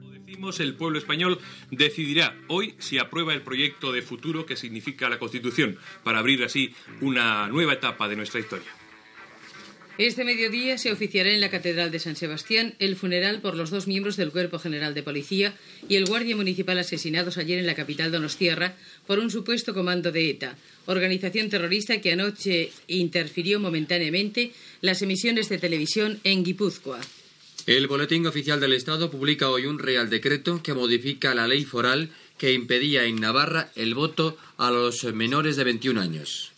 Informatiu
Extret del programa "El sonido de la historia", emès per Radio 5 Todo Noticias el 08 de desembre de 2012